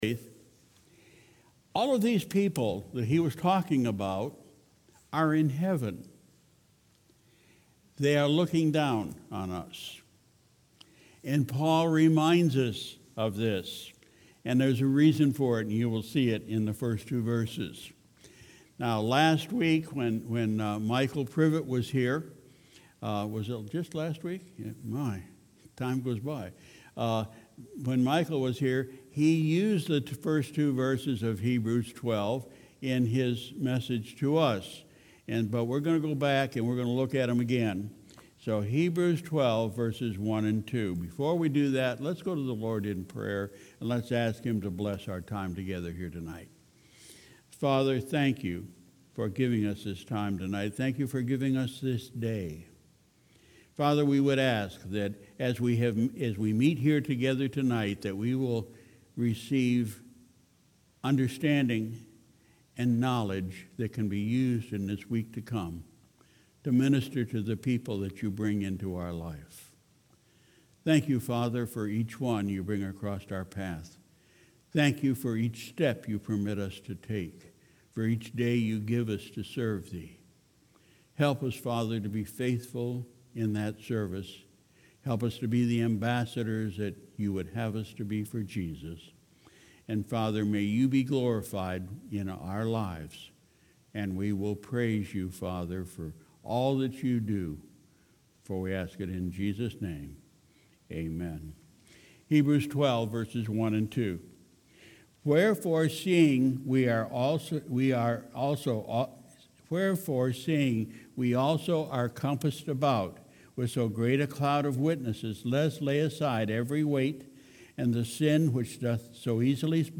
Sunday, July 28, 2019 – Evening Service